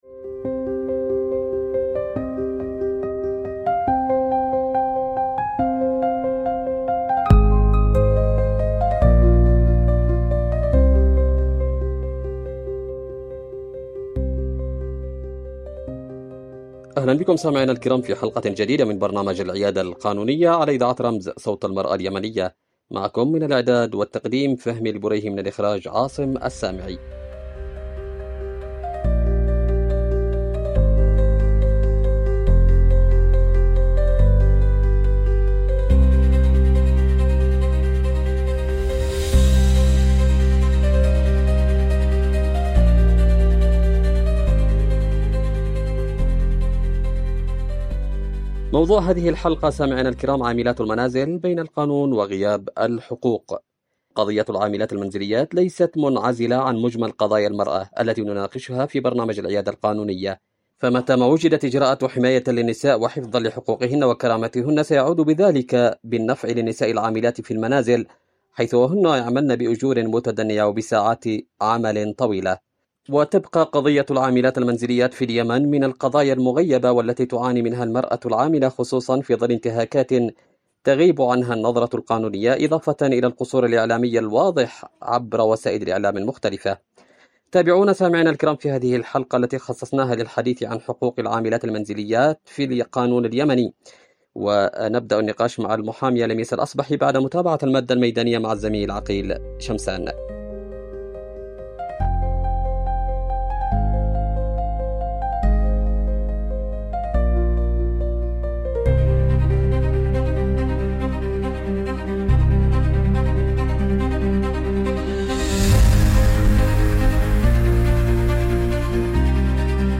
ضيفة الحلقة المحامية